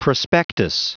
Prononciation du mot prospectus en anglais (fichier audio)
Prononciation du mot : prospectus